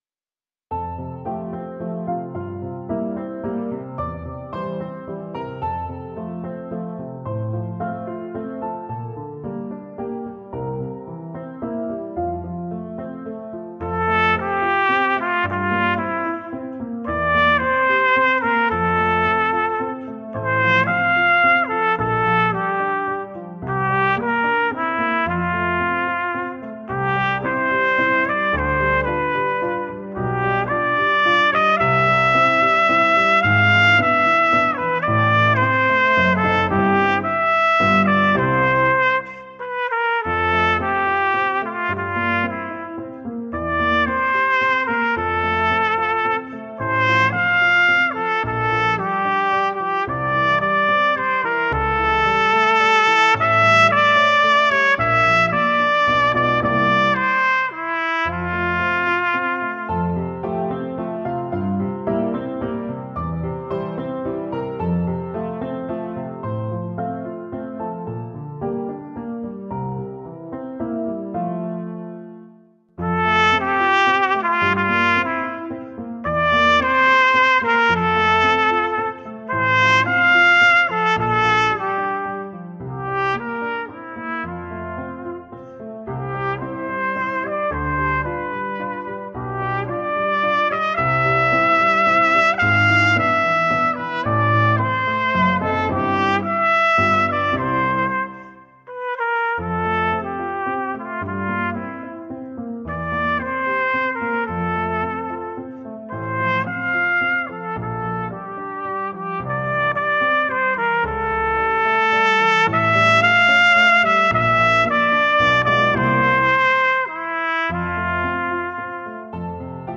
Delightful nostalgic melodies for cornet solo.